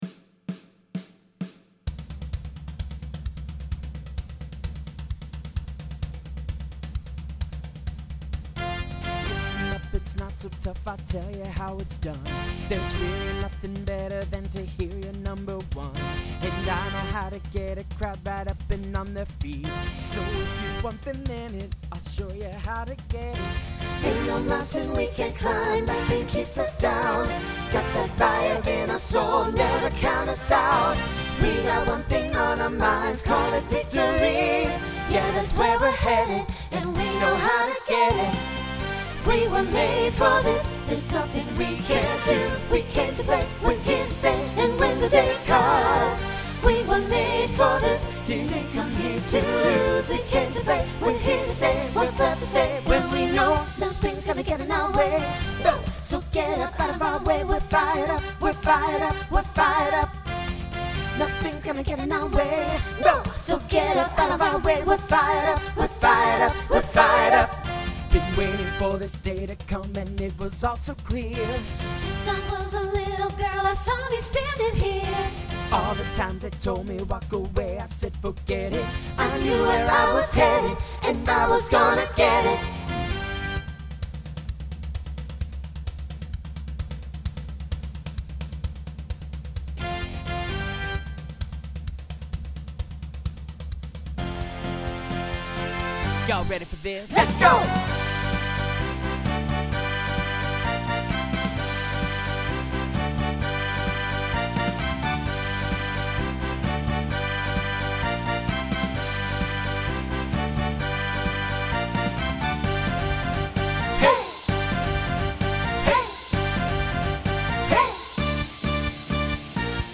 Genre Broadway/Film , Pop/Dance Instrumental combo
Voicing SATB